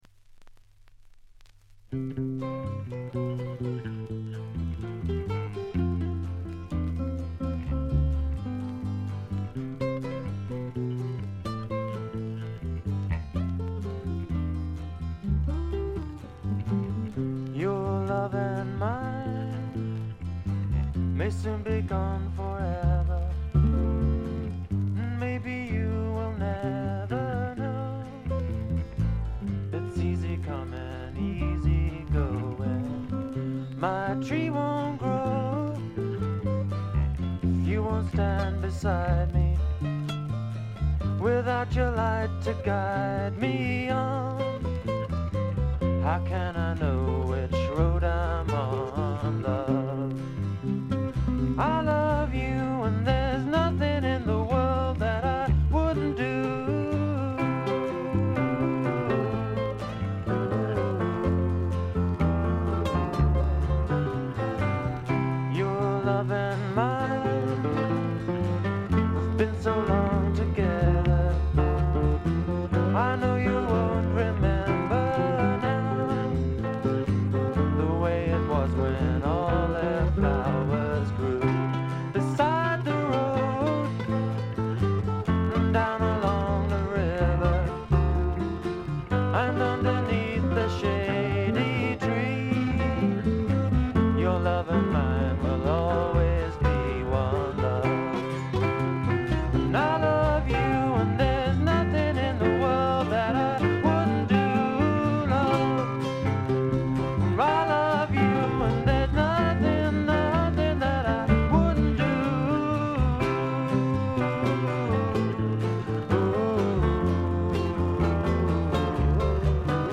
静音部で軽微なチリプチやバックグラウンドノイズ。
非トラッド系英国フォーク至宝中の至宝。
試聴曲は現品からの取り込み音源です。